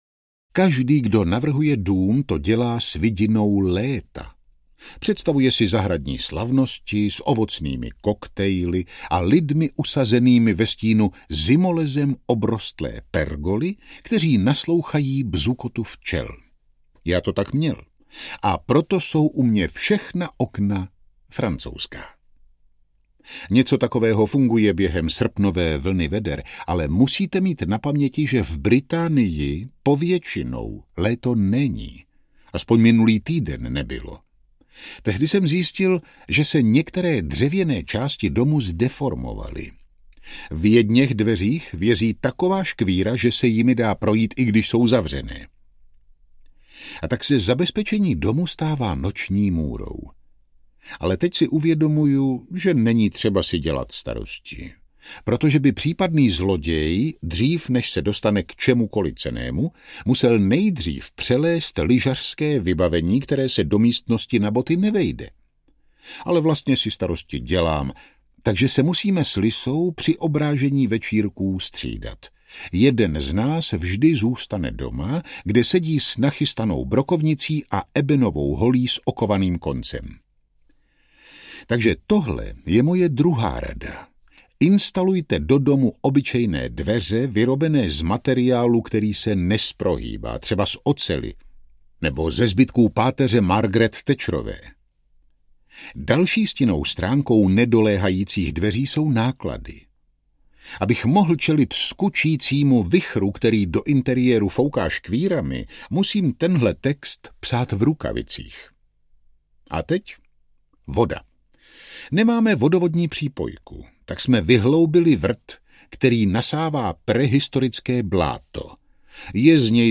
Hörbuch
MP3 Audiobook,